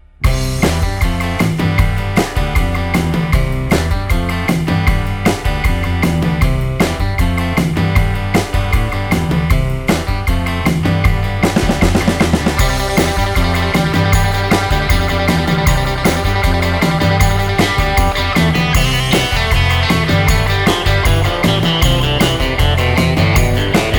no Backing Vocals Rock 'n' Roll 2:23 Buy £1.50